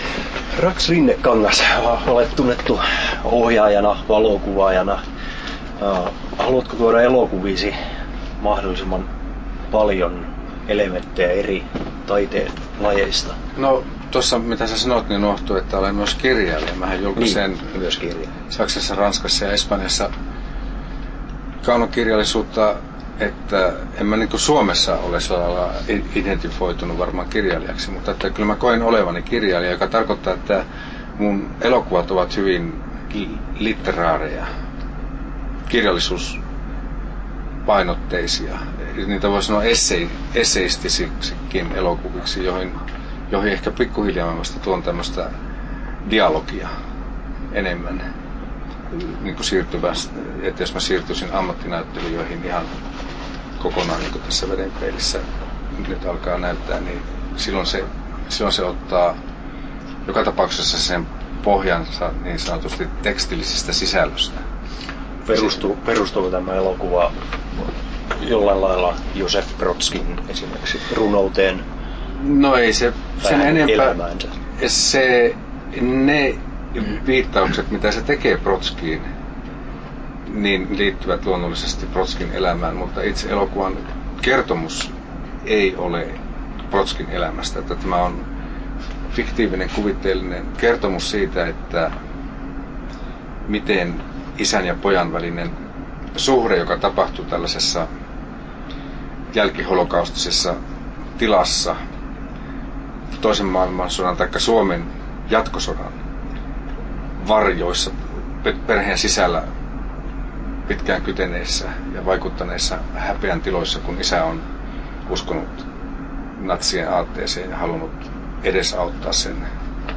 Haastattelut
12'15" Tallennettu: 16.10.2012, Turku Toimittaja